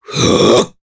Nicx-Vox_Casting3_jp.wav